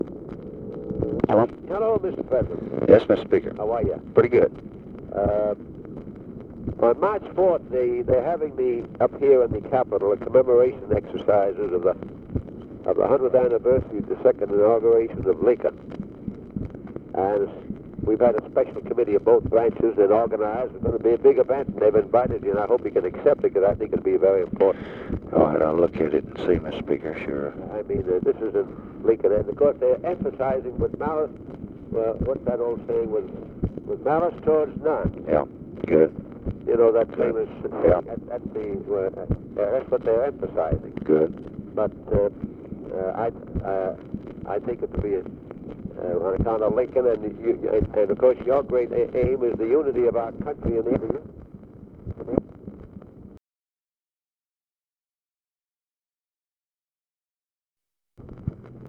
Conversation with JOHN MCCORMACK, February 27, 1965
Secret White House Tapes